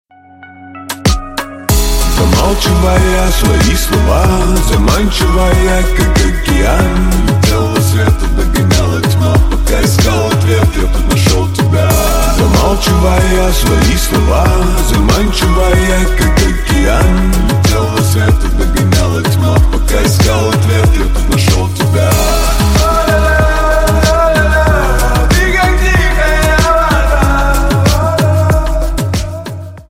Рэп Хип-Хоп Рингтоны